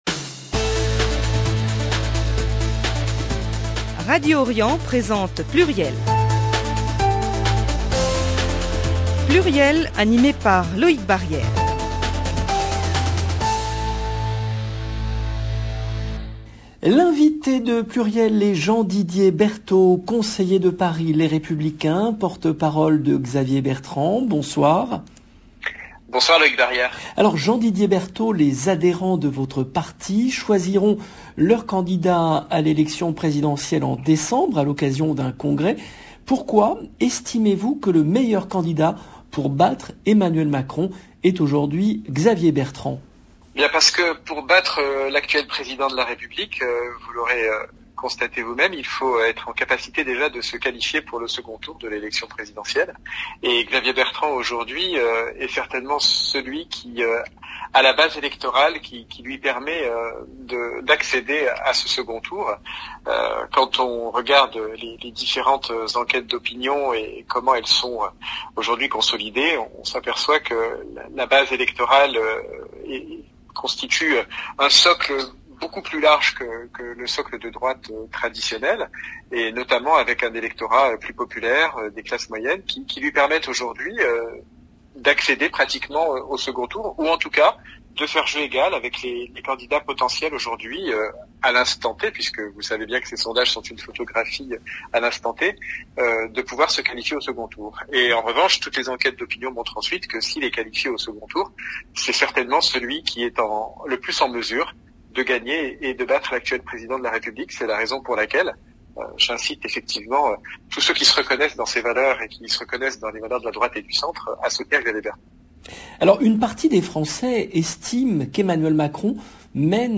L’invité de Pluriel est Jean-Didier Berthault , conseiller de Paris Les Républicains, porte-parole de Xavier Bertrand